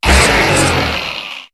Cri de Minotaupe dans Pokémon HOME.